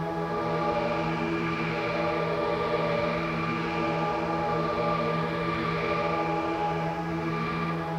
STK_Drone1Proc06_E.wav